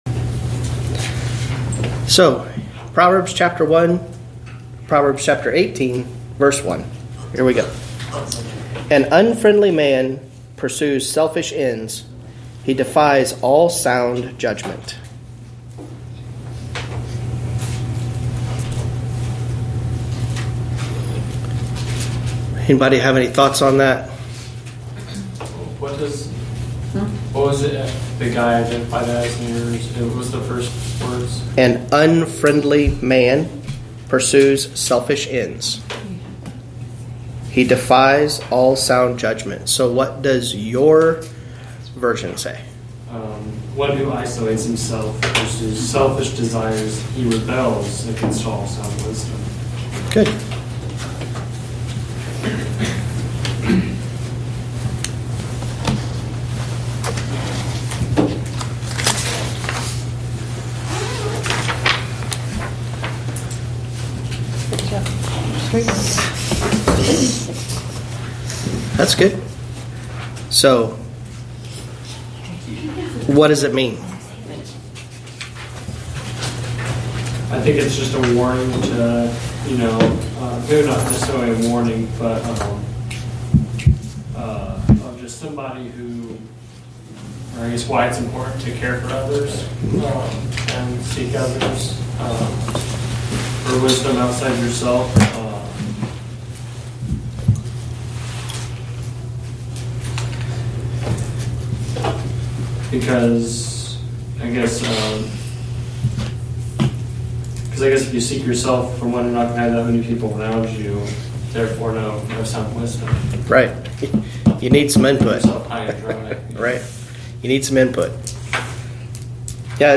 Wedensday Evening Bible Study Prov. 18:1-14